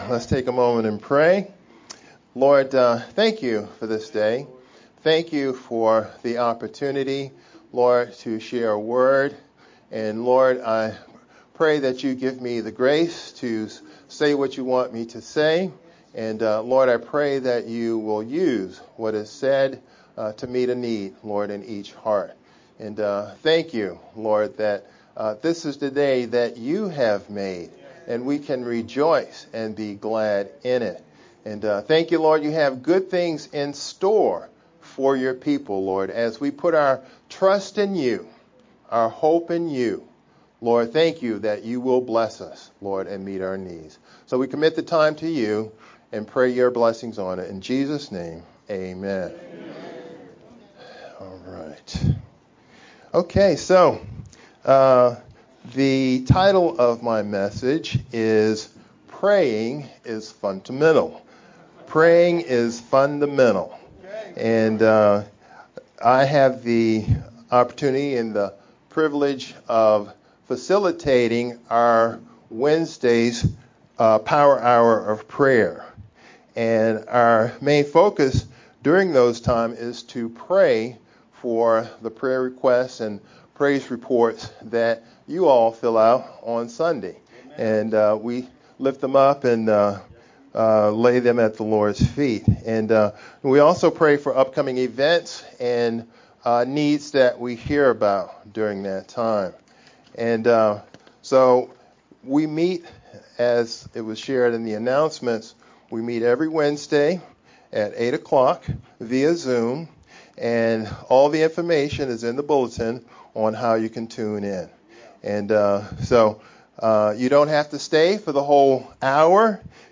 Aug-17th-VBCC-edited-sermon-only_Converted-CD.mp3